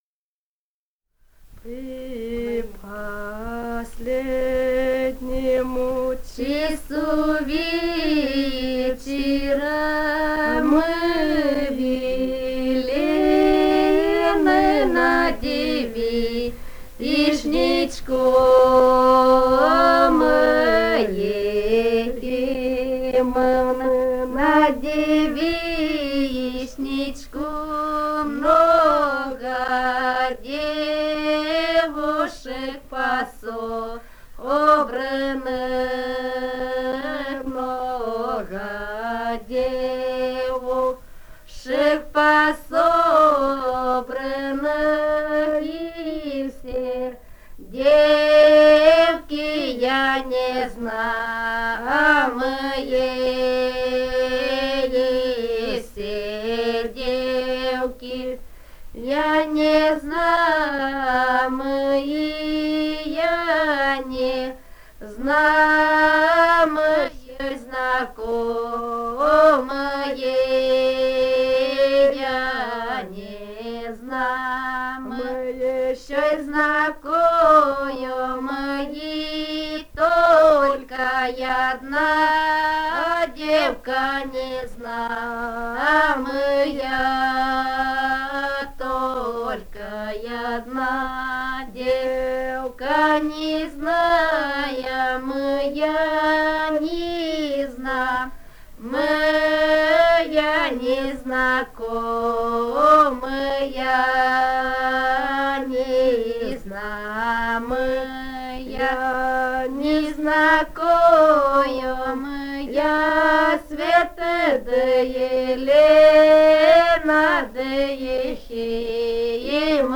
Этномузыкологические исследования и полевые материалы
Румыния, с. Переправа, 1967 г. И0973-18